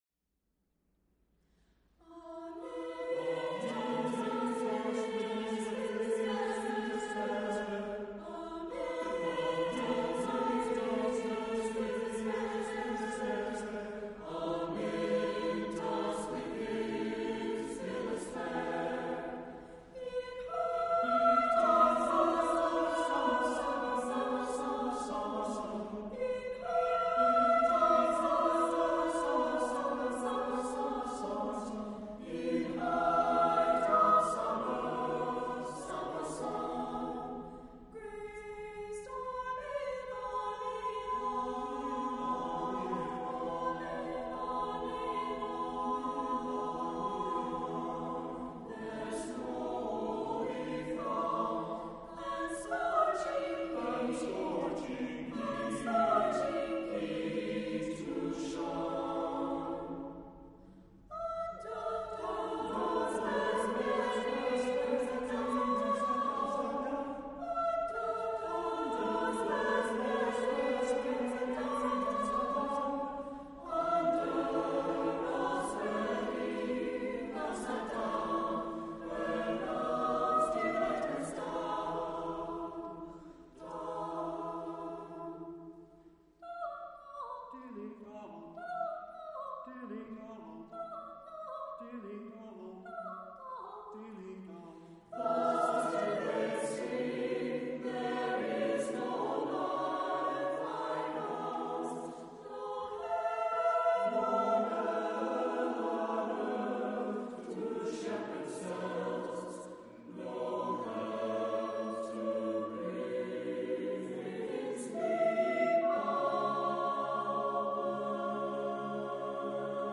• SATB a cappella
• This contemporary madrigal speaks for itself.
is a mix of Renaissance and 21st-Century styles.
This piece is light and rhythmically challenging.